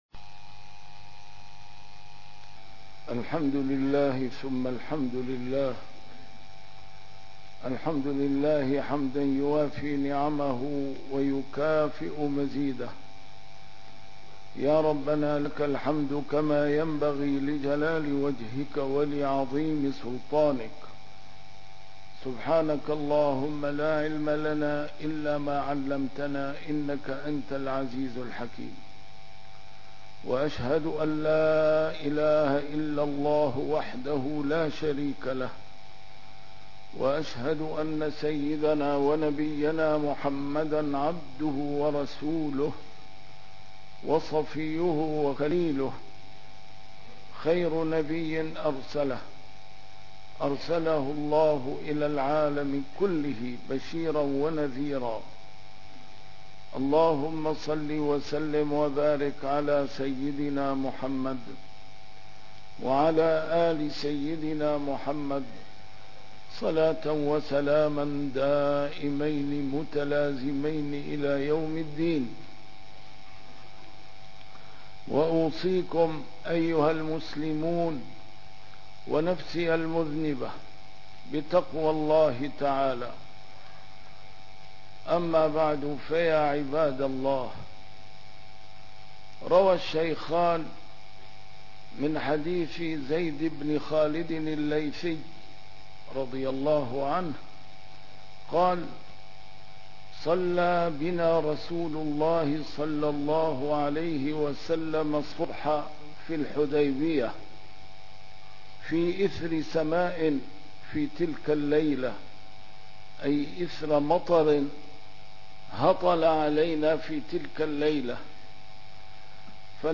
A MARTYR SCHOLAR: IMAM MUHAMMAD SAEED RAMADAN AL-BOUTI - الخطب - هل حافظنا على نعمة الماء؟